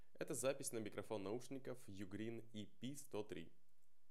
Микрофон
Качество микрофона хорошее, голос слышно тихо, по неведомой причине. Записывал на диктофон, после подключения в Mac.
Рабочий и даже хорошо пишет голос. НО, пишет на маке тихо.